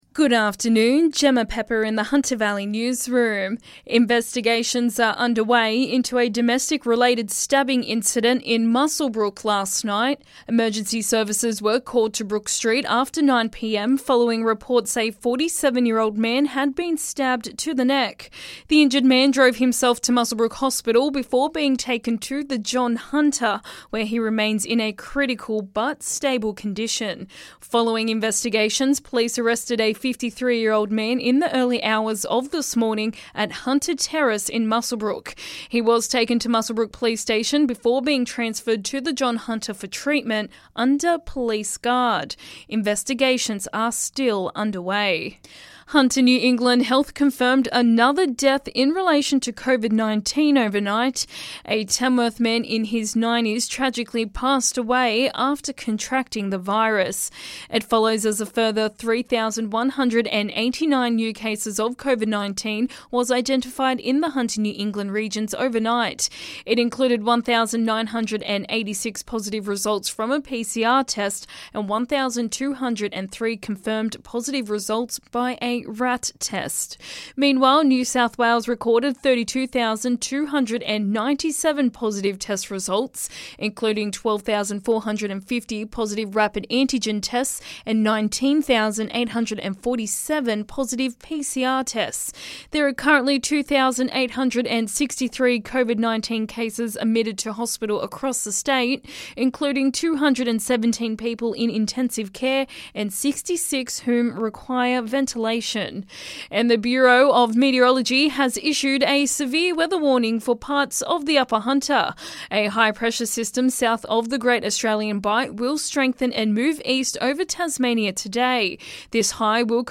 LISTEN: Hunter Valley Local News Headlines 19/01/2022